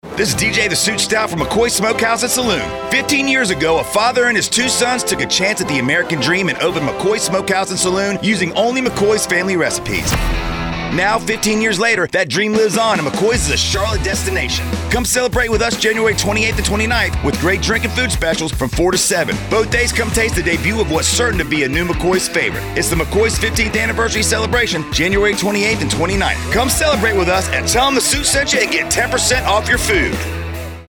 Radio
McKoys 15th Anniversary Spot